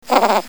cartoon21.mp3